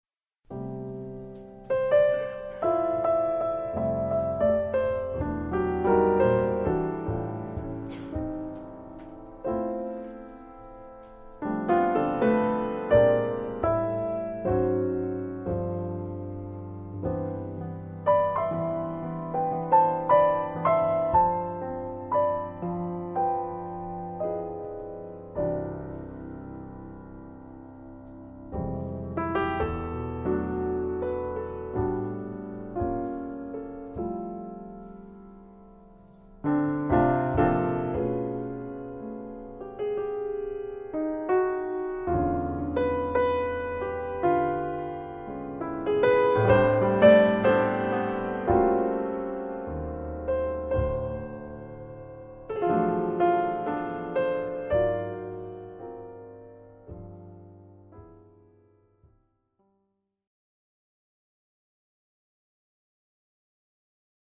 Recorded at The New School Jazz Performance Space,